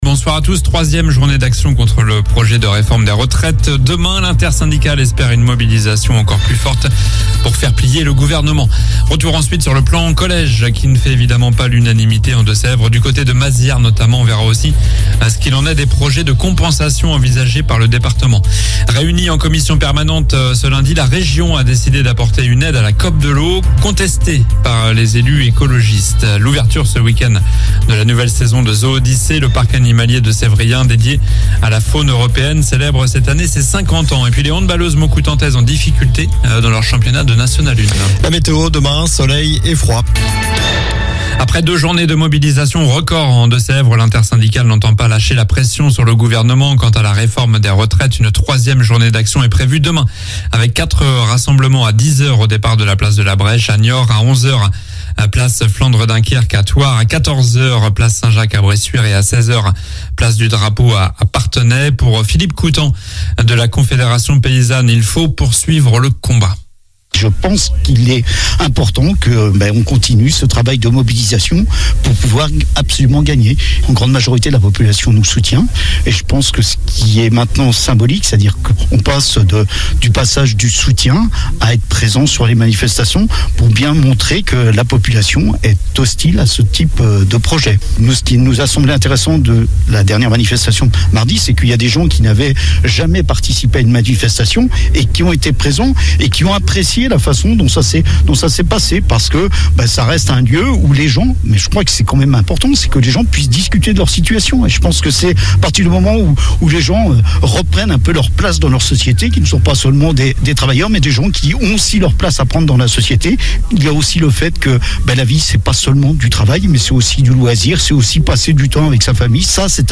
Journal du lundi 06 février (midi)